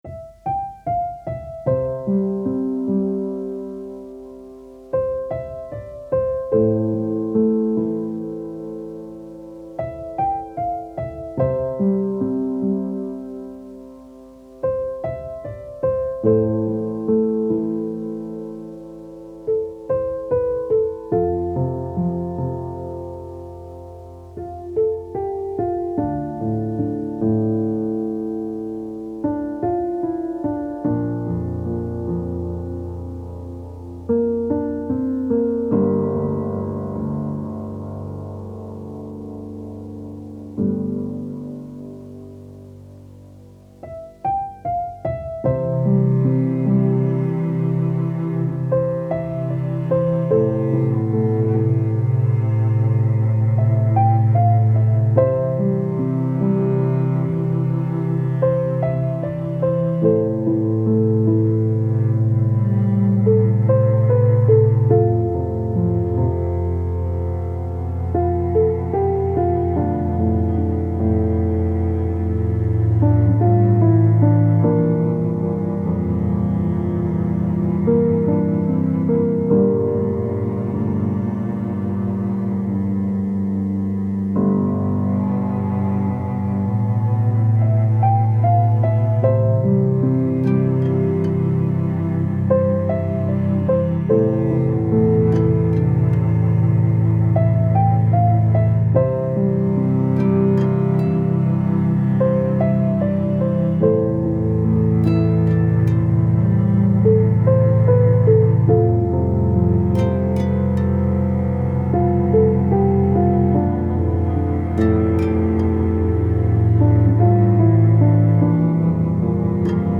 Style Style Soundtrack
Mood Mood Relaxed, Sad
Featured Featured Acoustic Guitar, Cello, Piano
BPM BPM 74